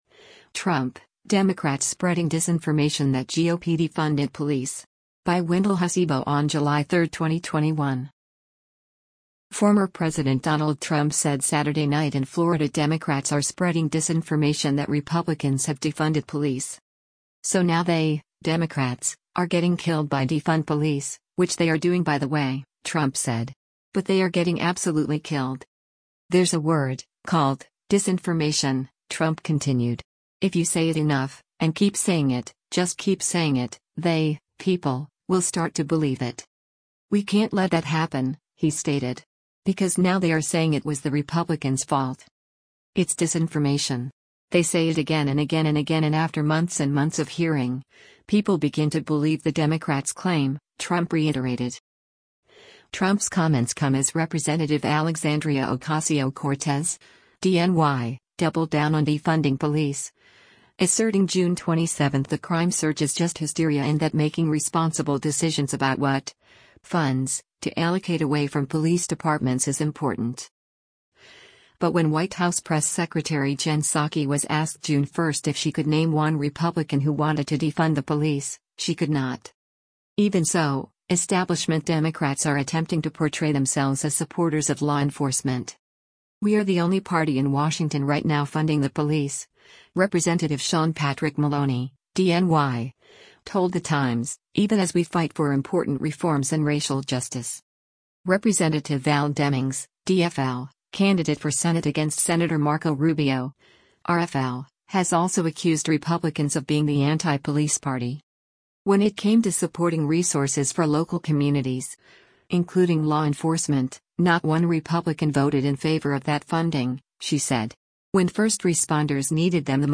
Former President Donald Trump said Saturday night in Florida Democrats are spreading “disinformation” that Republicans have defunded police.